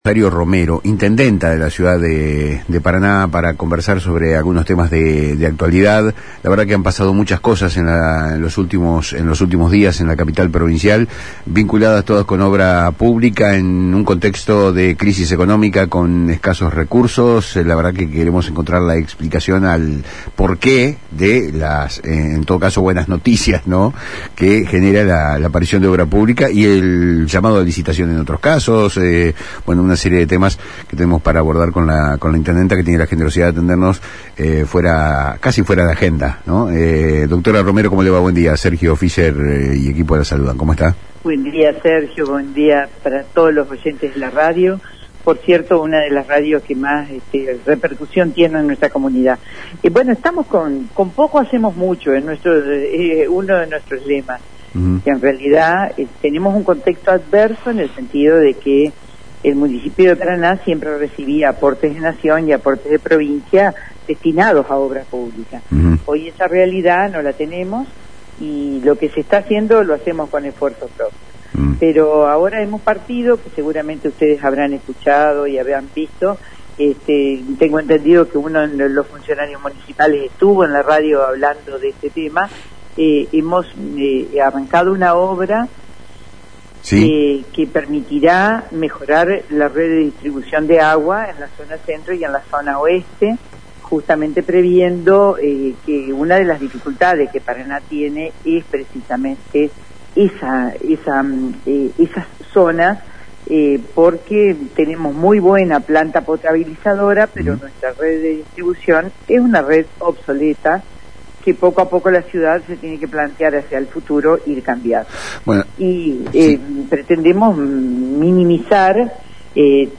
La intendenta de Paraná, Rosario Romero, brindó, en diálogo con Palabras Cruzadas por FM Litoral, detalles sobre la gestión de obra pública en la ciudad, destacando los esfuerzos realizados en un contexto de crisis económica y escasez de recursos.
Lo más destacado de la entrevista: